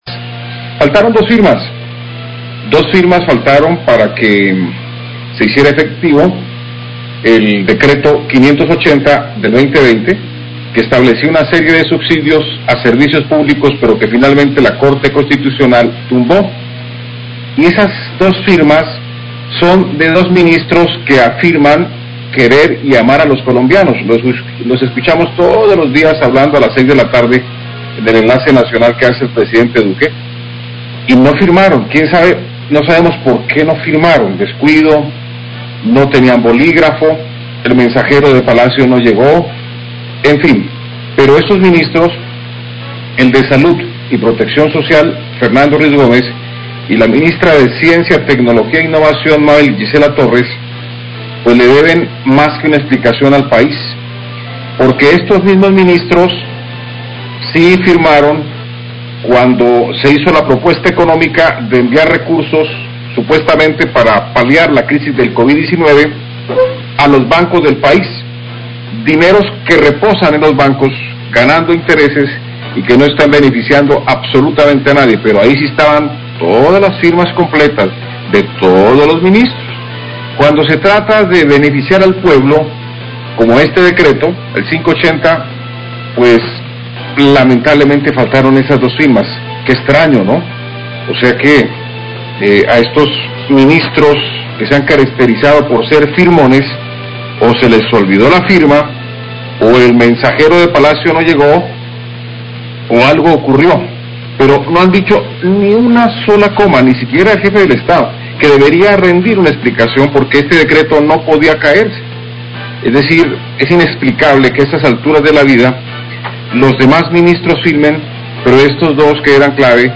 Radio
columna de opinión